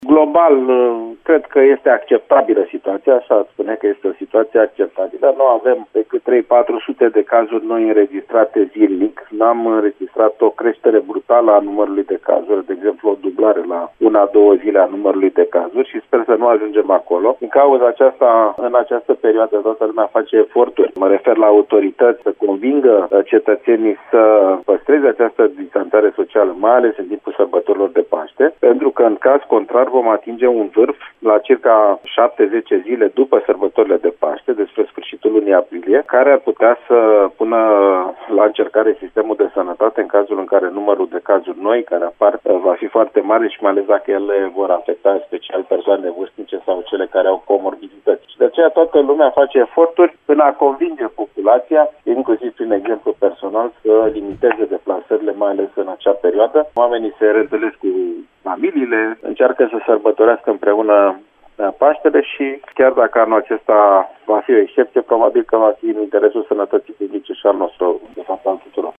Dacã nu vom ţine cont de sfaturile autoritãţilor imediat dupã sãrbãtorile pascale sistemul medical din România va fi greu încercat din cauza numãrului mare de persoane depistate cu COVID-19 şi nu ne dorim asta, atrage atenţia Alexandru Rafila: